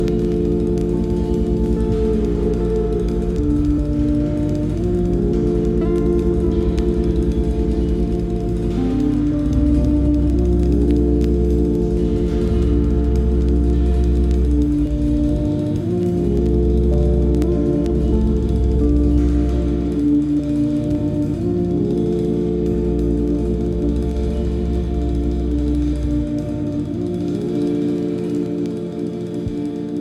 New Release Experimental Jazz